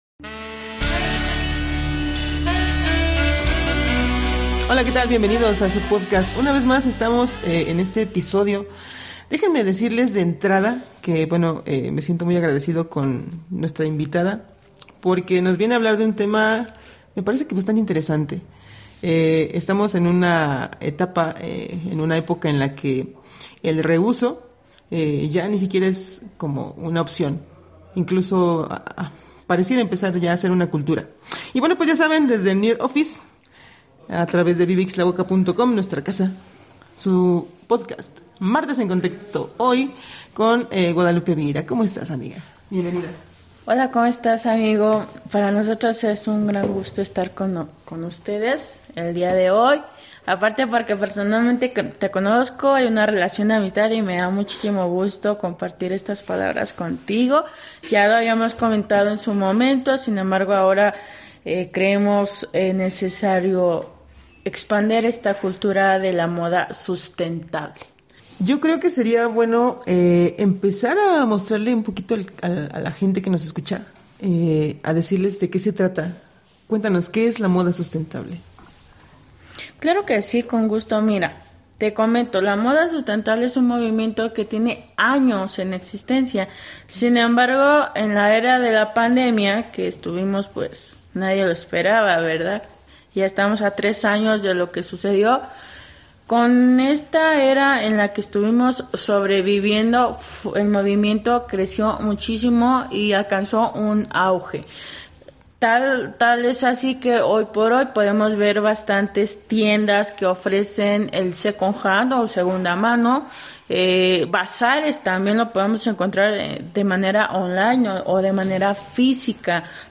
Locación: NEAR OFFICE.